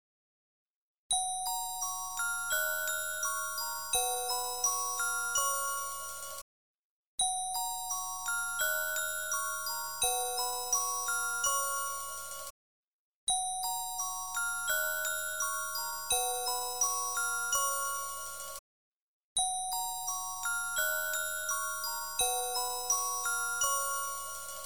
• Качество: 320, Stereo
колокольчики
ксилофон
рождественские
Мелодия ксилофона